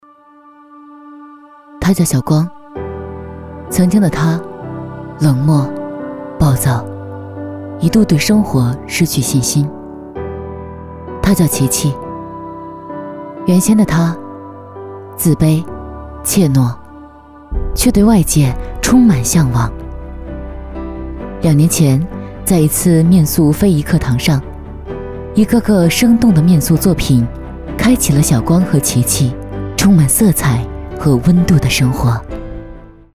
女85号-独白-小光
女85磁性女配 v85
女85号-独白-小光.mp3